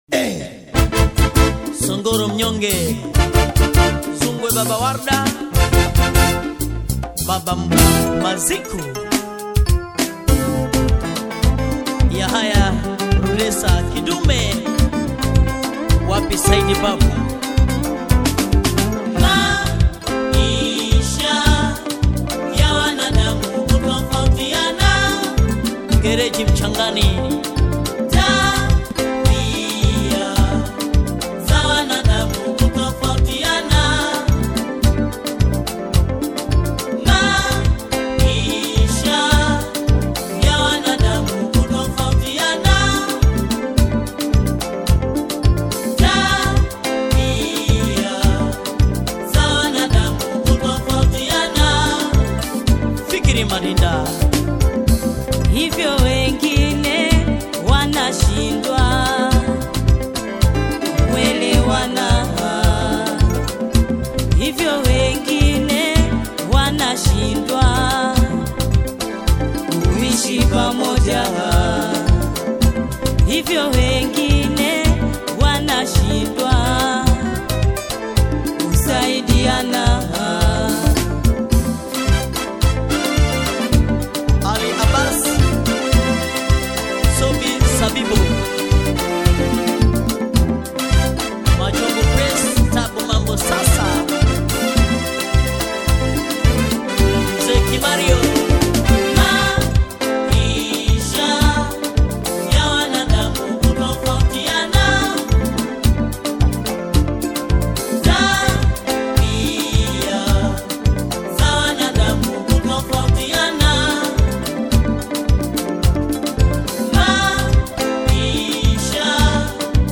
AudioTanzaniaZilipendwa